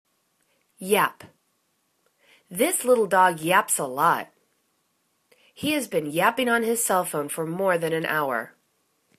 yap     /yap/    v